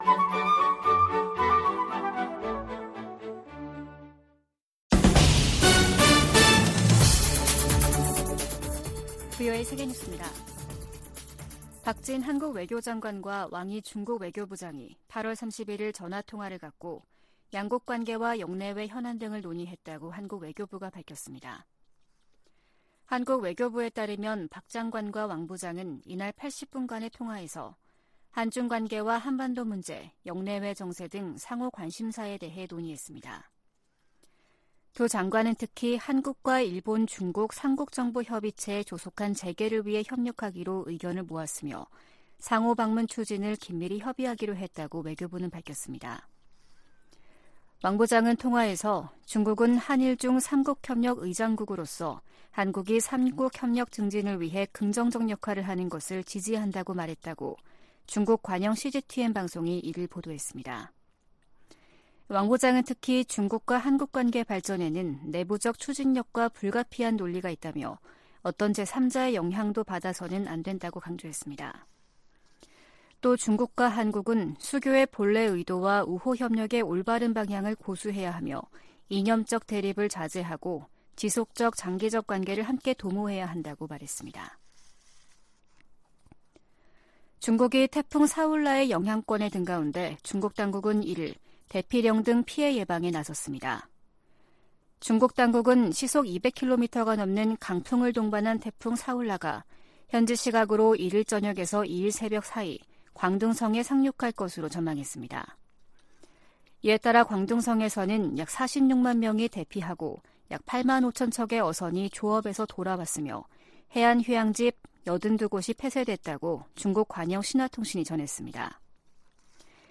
VOA 한국어 아침 뉴스 프로그램 '워싱턴 뉴스 광장' 2023년 9월 2일 방송입니다. 미국 재무부가 북한의 대량살상무기(WMD)와 탄도미사일 개발에 자금조달을 해온 북한인과 러시아인들을 제재했습니다. 미 의회 산하 중국위원회가 유엔 인권과 난민 기구에 서한을 보내 중국 내 탈북민 북송을 막도록 개입을 요청했습니다. 미 국방부가 북한에 러시아와의 무기 거래 협상 중단을 촉구했습니다.